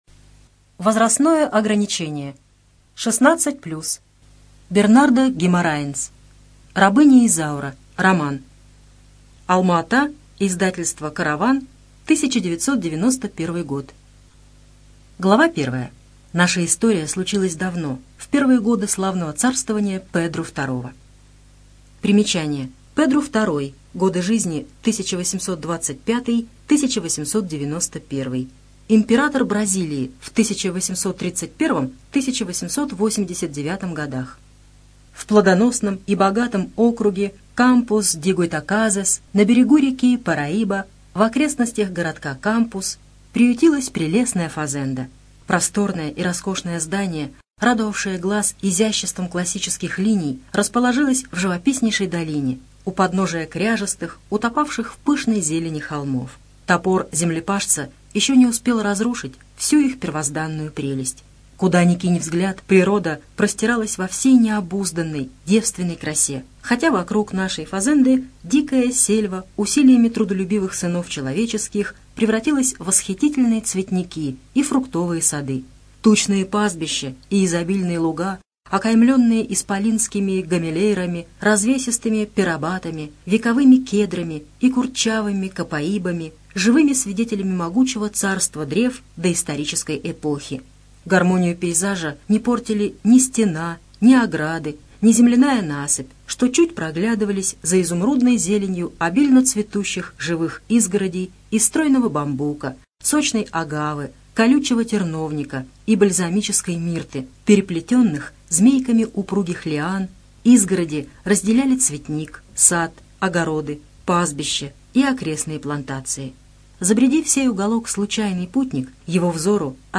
Студия звукозаписиКемеровская областная специальная библиотека для незрячих и слабовидящих